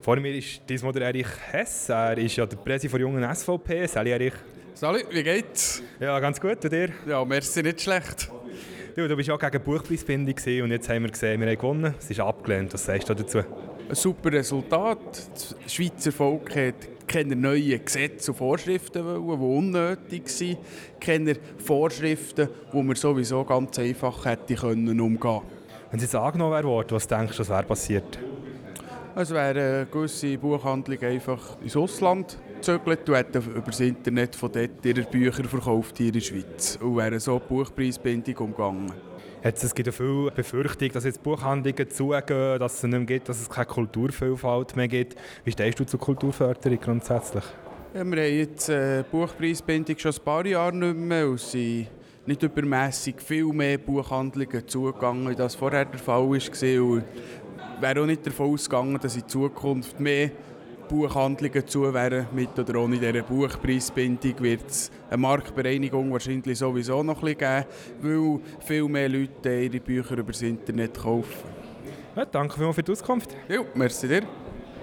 Abstimmungsparty - Erich Hess